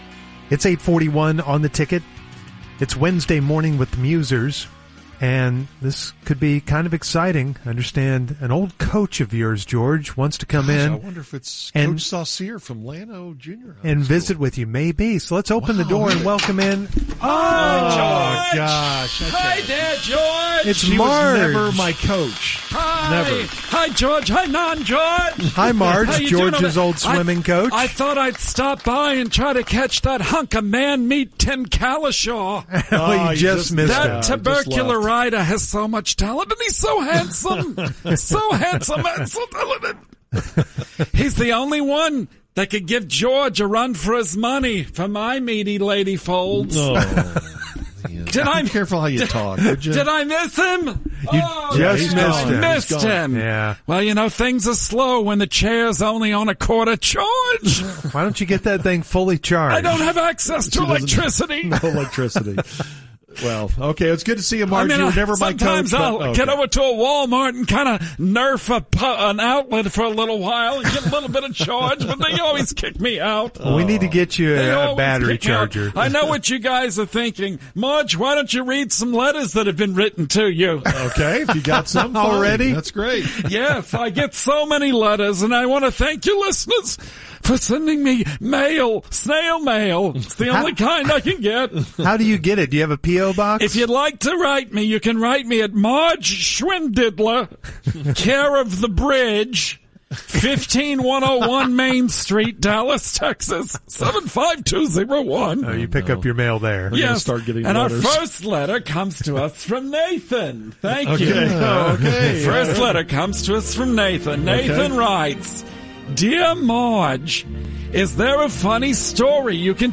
Marge reads some letters from listeners